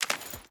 Water Chain Jump.ogg